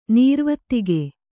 ♪ nīrvattige